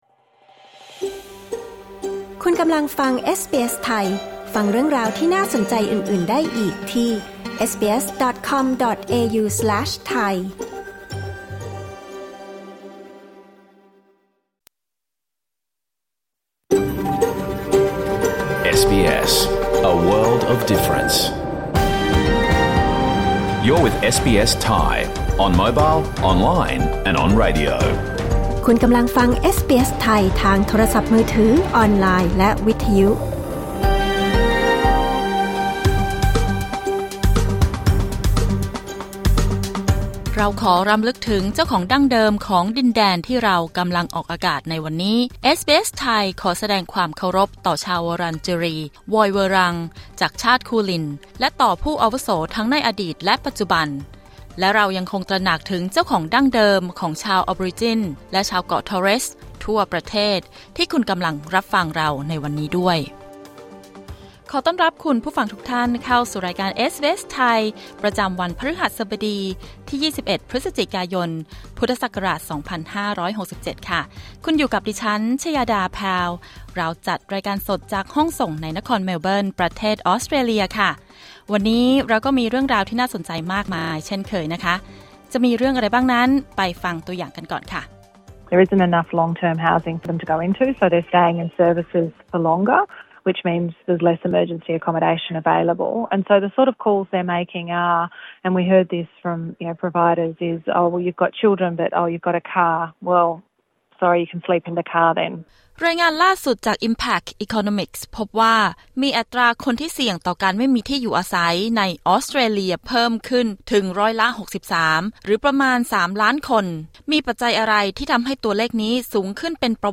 รายการสด 21 พฤศจิกายน 2567